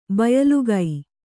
♪ bayalugai